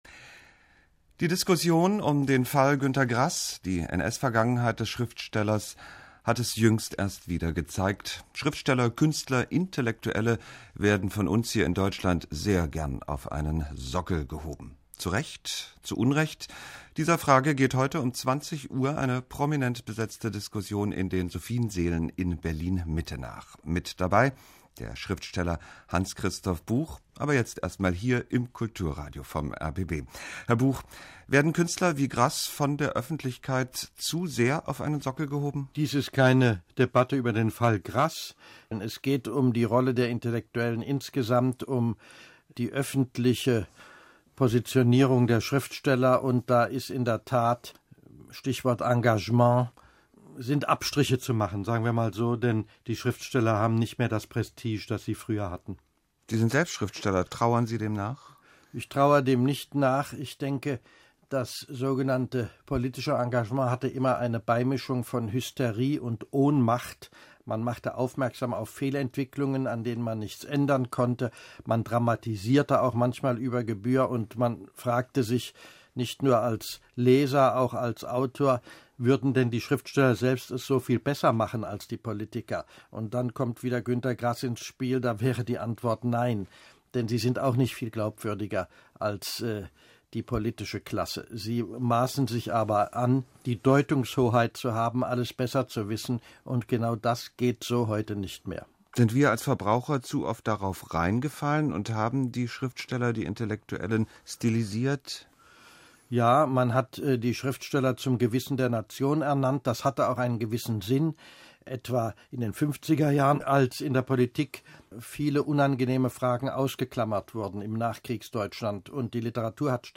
Zur Diskussion über die öffentliche Rolle von Literaten und das Verhältnis von Schriftstellerei und Politik lud die Heinrich-Böll-Stiftung am 21.Februar 2007 in die Berliner Sophiensaele ein.
Wir dokumentieren die Veranstaltung in gekürzter Form.